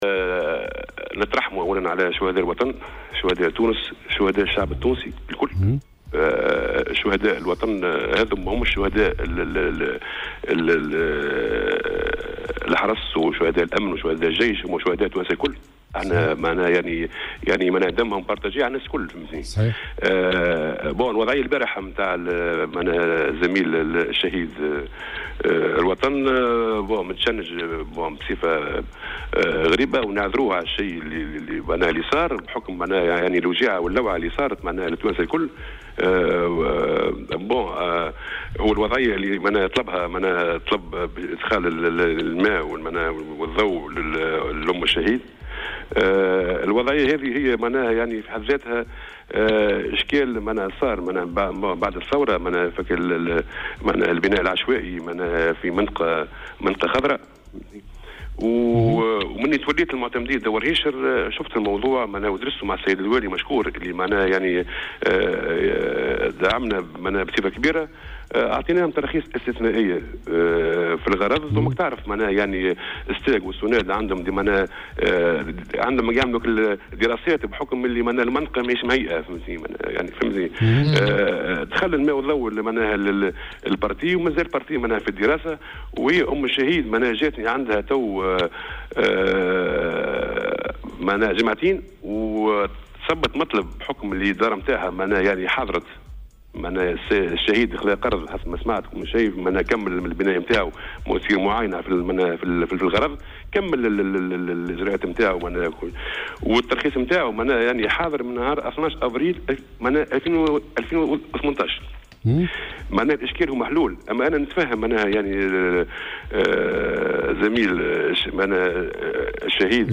وفي تعليقه على هذا الفيديو، قال معتمد دوار هيشر، محمد الباجي في مداخلة له اليوم في برنامج "صباح الورد"، إنه يتفهّم ألمه و حزنه الشديد، مشيرا إلى أنه تم منح تراخيص استثنائية لمتساكني هذه الأحياء التي شيّدت عشوائيا في منطقة خضراء، وذلك في انتظار القيام بالدراسات الضرورية من قبل "الستاغ" و"الصوناد" لاستكمال ربط بقية المنازل بشبكة التنوير والماء الصالح للشراب.